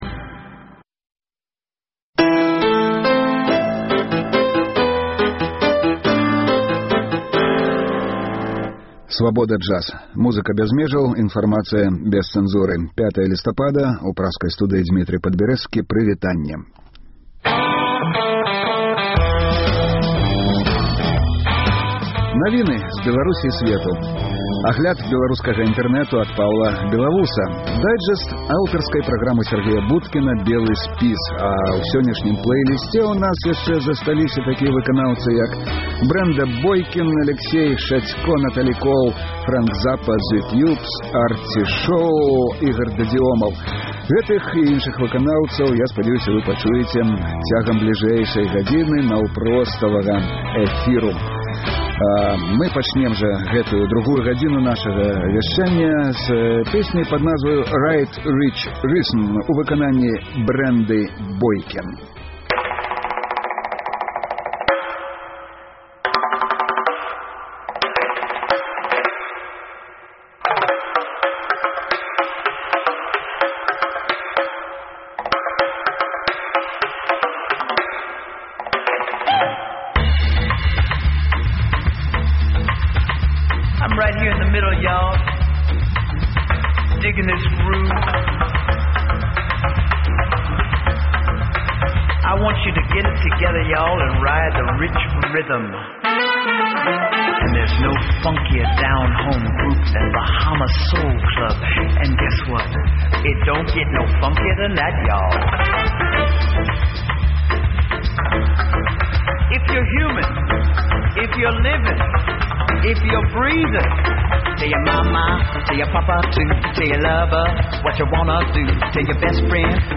Інтэрнэт-радыё Svaboda Jazz. Слухайце ад 12:00 да 14:00 жывы эфір Свабоды!